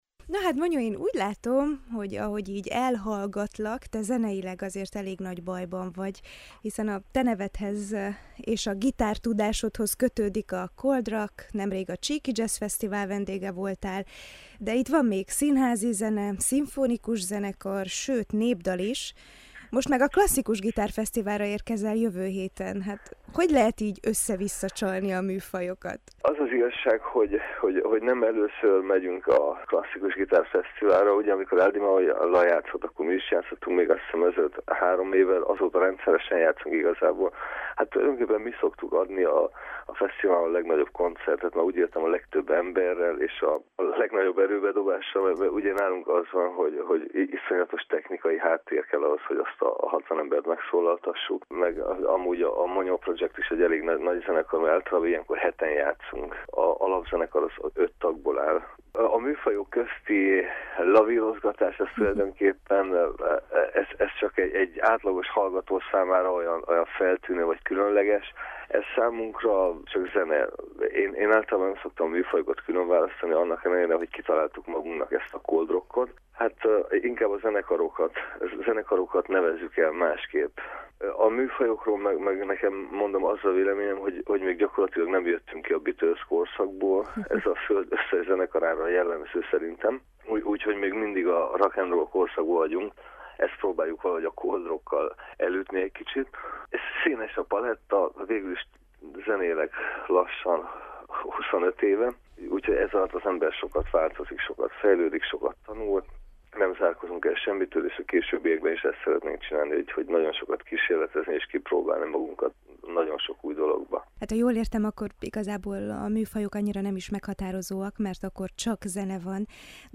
A KULTÚRPRESSZÓ vendége a frontember